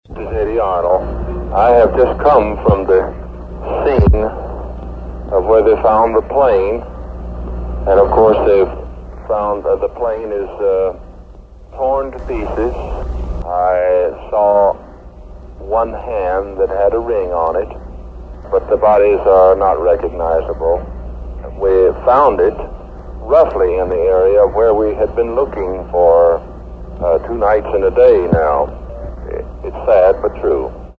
Singer Eddy Arnold was one of the searchers and talked with CBS News. His comments were heard in Chattanooga over WDOD AM 1310.
EDDY ARNOLD IDENTIFIES JIM REEVES BODY - CBS NEWS .mp3